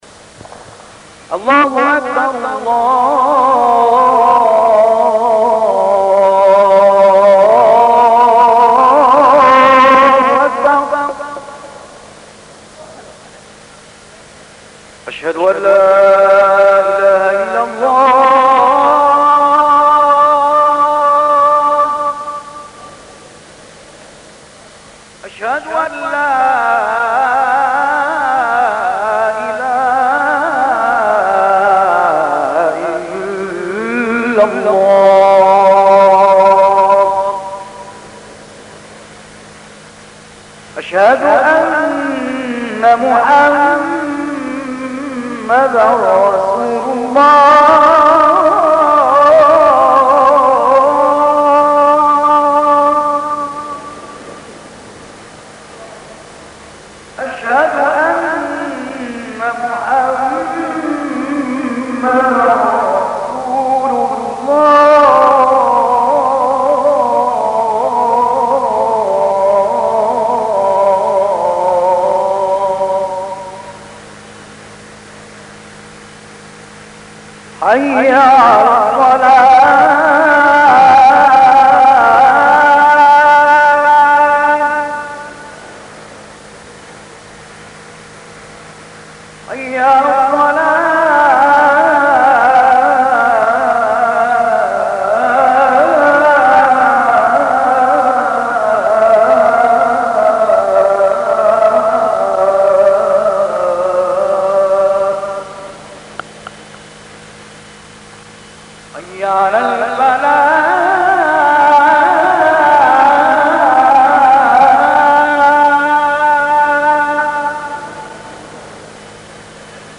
Aazan of Famous Individuals - Page 1
03. Aazan of Famous Individuals 03.mp3